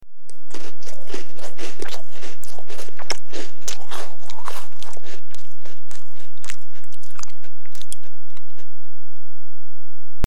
Wav sample: Chewing Cracker 2
Chewing a cracker (WAV file)
Relevant for: eat,, crunch.
Try preview above (pink tone added for copyright).
Tags: chew , chewing